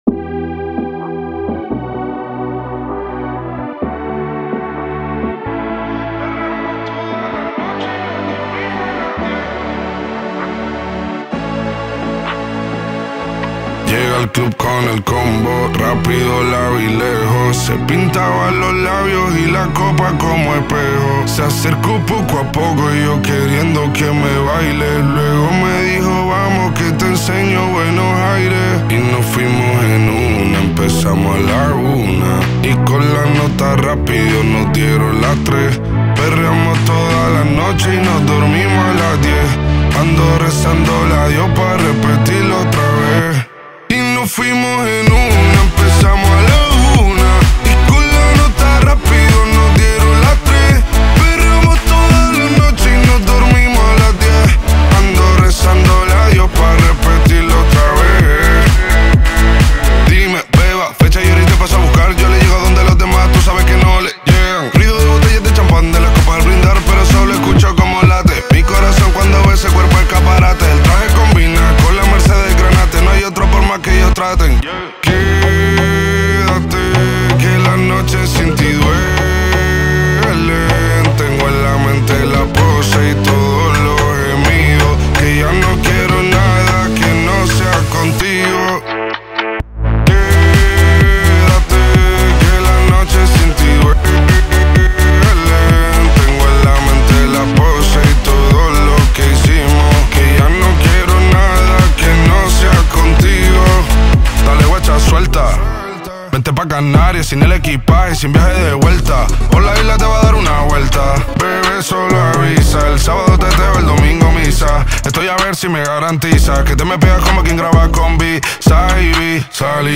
Carpeta: Reggaeton y + mp3